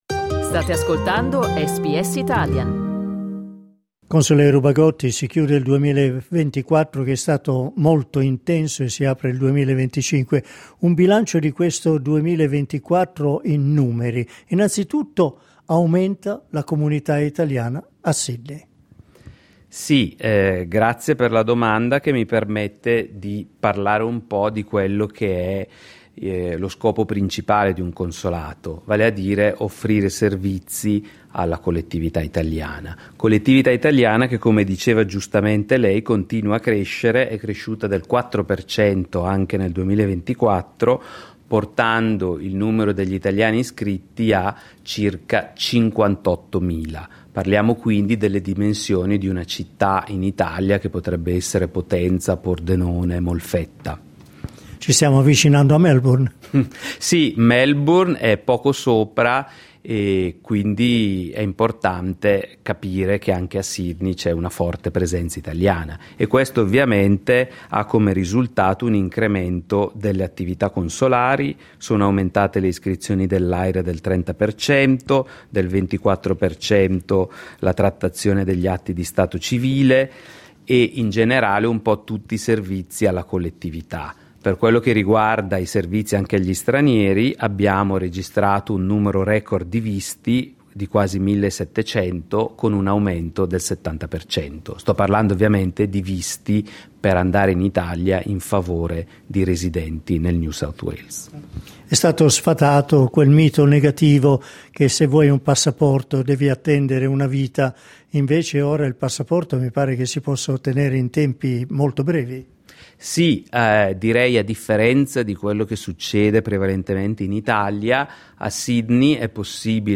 Clicca sul tasto "play" in alto per ascoltare l'intervista con il console Particolarmente positivi i risultati delle emissioni di passaporti che si possono ottenere in un paio d'ore e senza appuntamento a Sydney, presentando la documentazione necessaria.